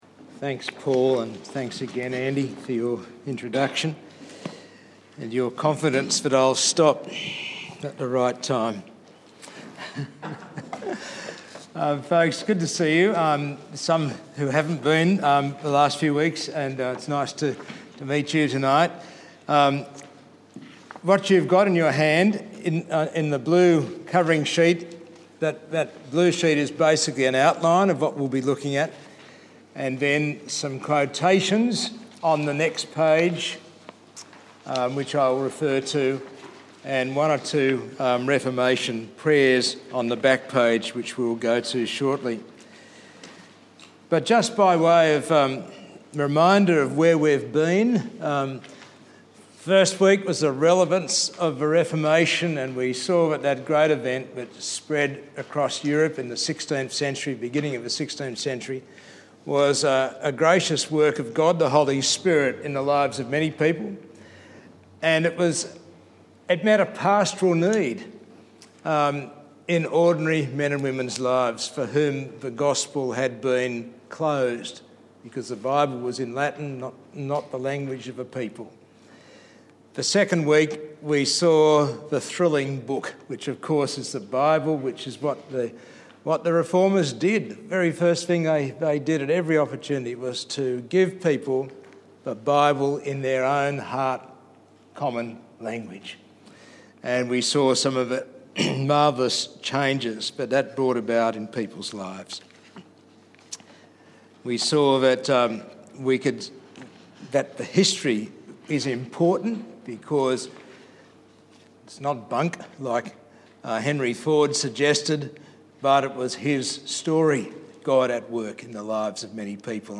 Service Type: Sunday evening service